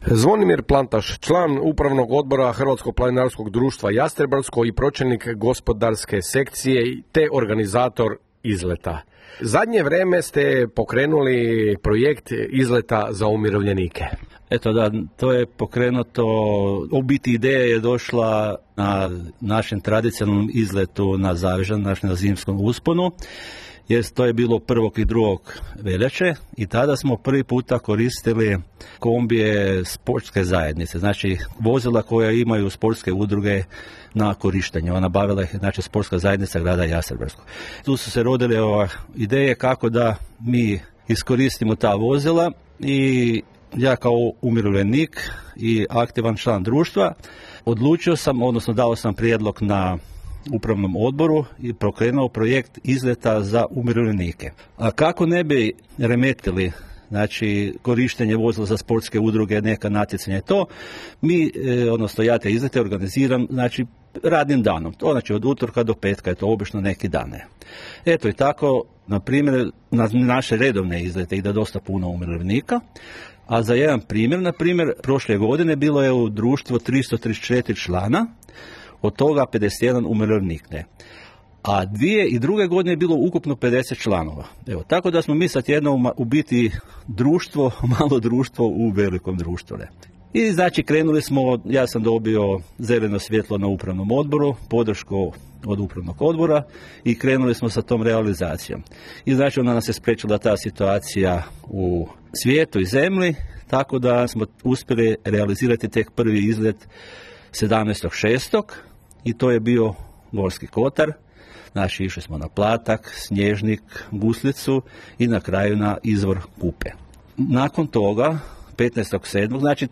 više je i o planiranom, i o dosadašnjim izletima, rekao našem novinaru.